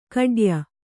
♪ kaḍya